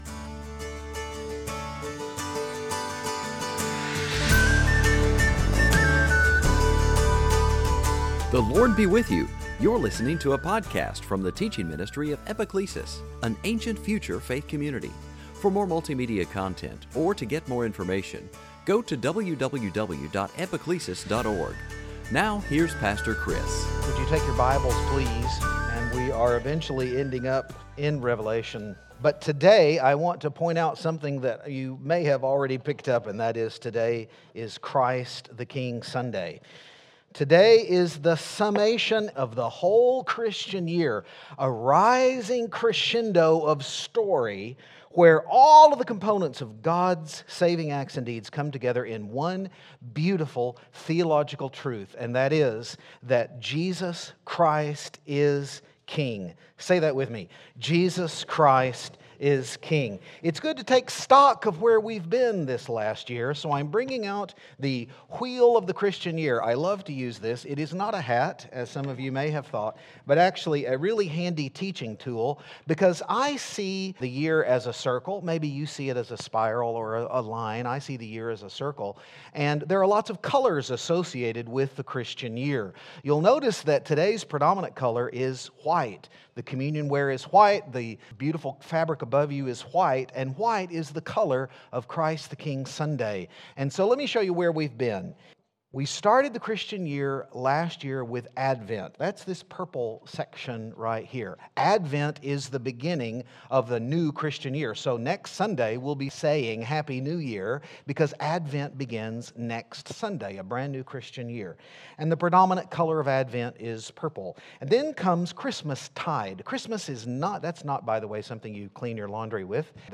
2018 Sunday Teaching authority Christ the King dominion sovereign Trinity Christ the King Sunday